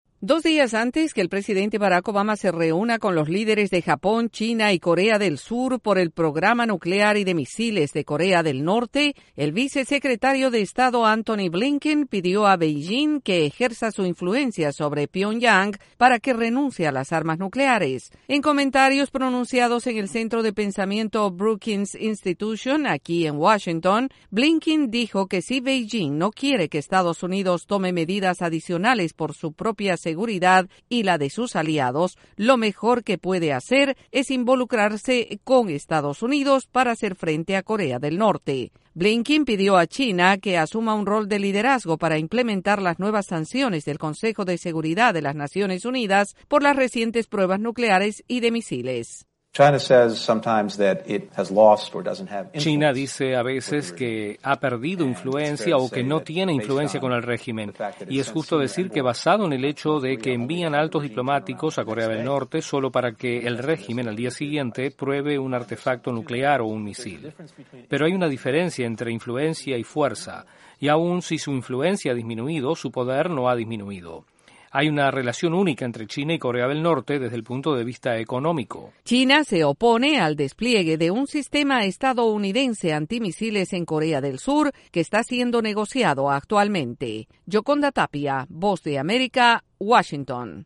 Estados Unidos pide a China utilizar sus influencias en Corea del Norte y su programa nuclear. Desde la Voz de América en Washington DC informa